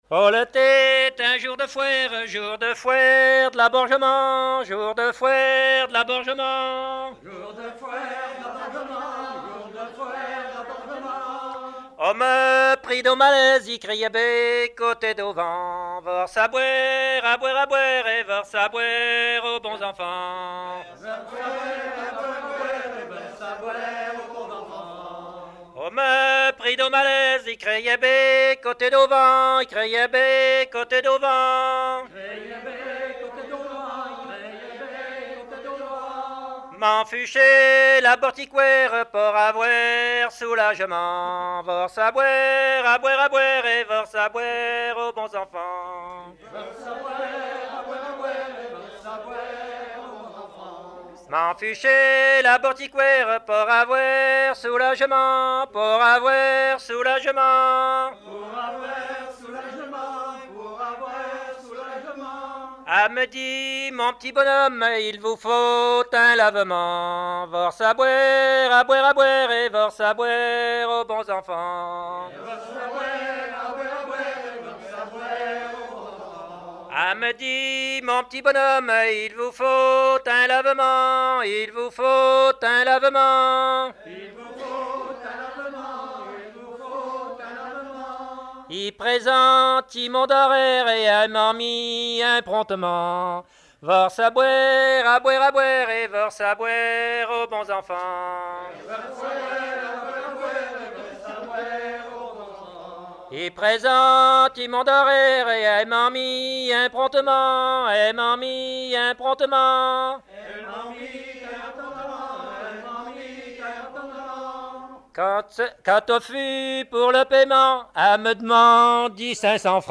Genre laisse
Après-midi autour de chanteurs à l'écomusée du Daviaud
Pièce musicale inédite